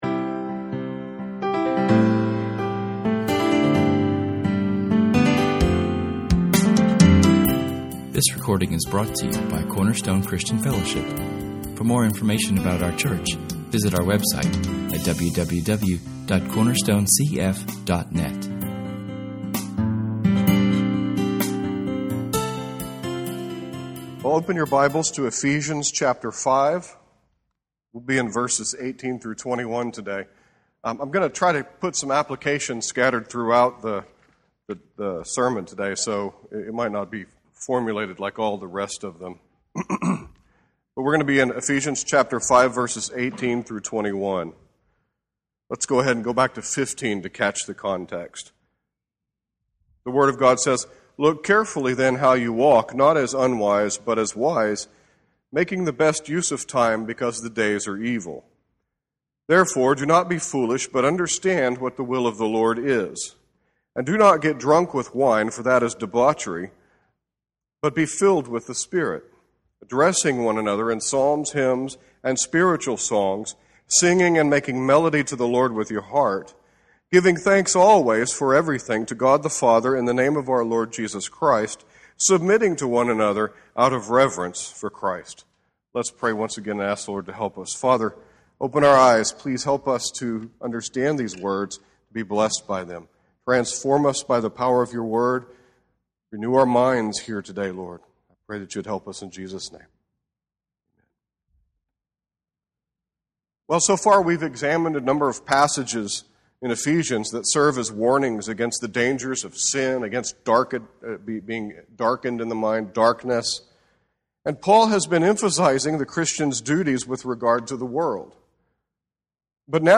Our sermon is taken from [esvignore]Ephesians 5:18-21[/esvignore]. We will examine the command (yes, the command) to be filled with the Spirit.